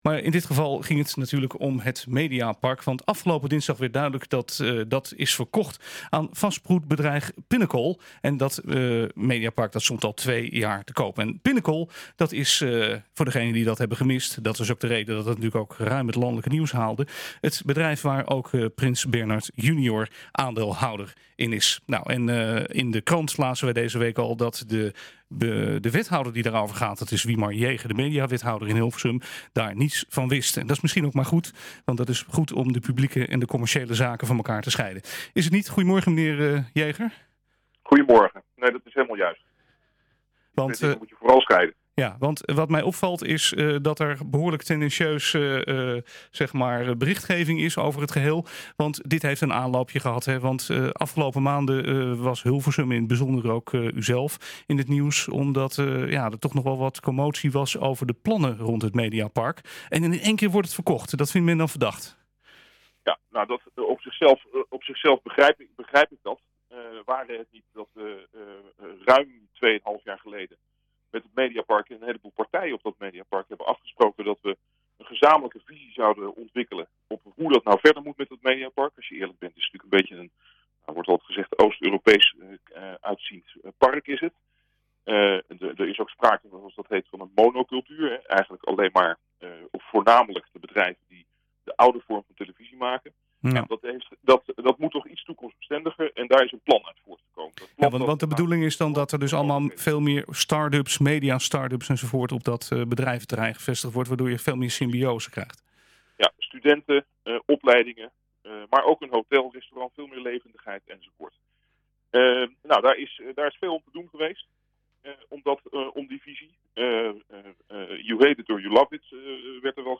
Afgelopen dinsdag werd duidelijk dat het Mediapark in Hilversum is verkocht aan vastgoedbedrijf Pinnacle. Het mediapark stond al 2 jaar te koop. Wethouder Wimar Jaeger vertelt waarom.